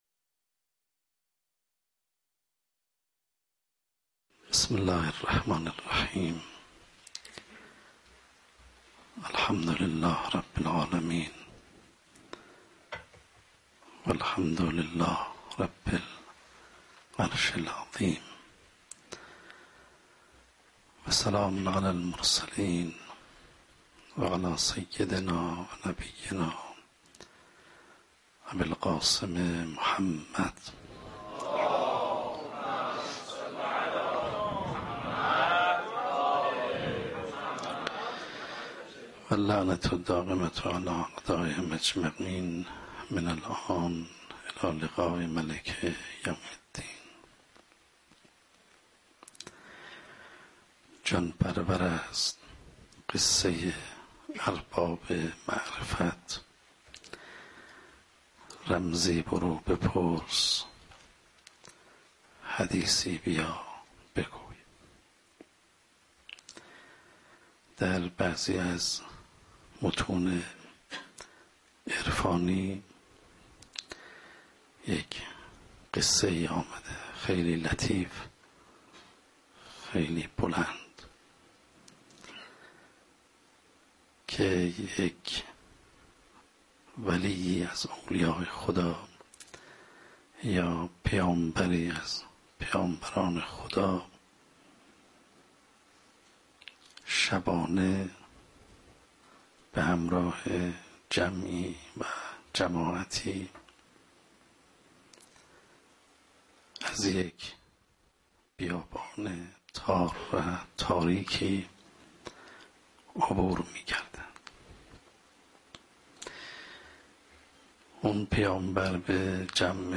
شب اول محرم 95 - سخنرانی - دنیا و استفاده انسان از آن